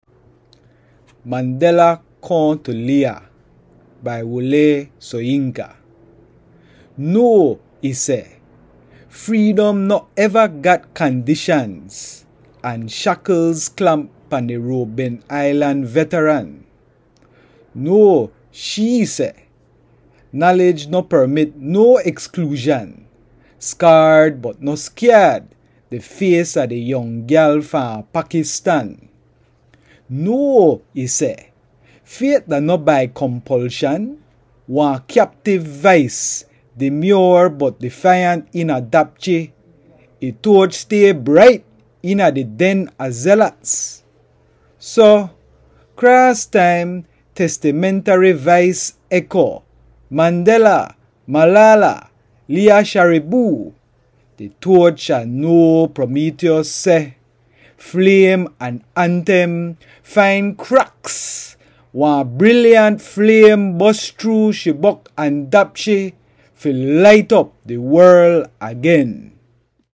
A reading of the poem in Belize Kriol